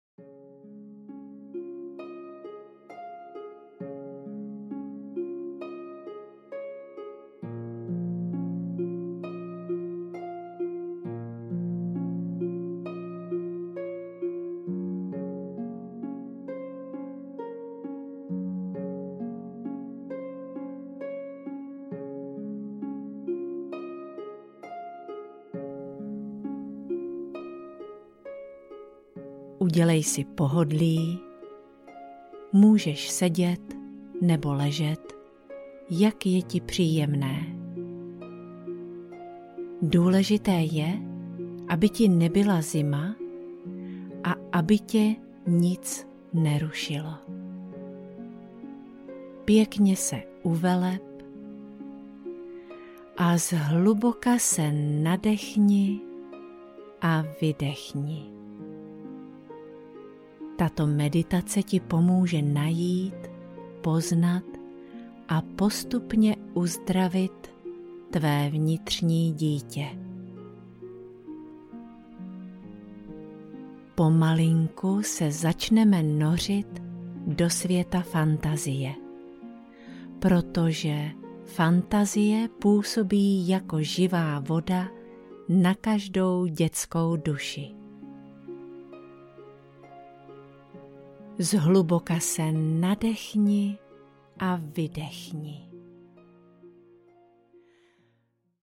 Uzdrav své vnitřní dítě - meditace pro dospělé audiokniha
Ukázka z knihy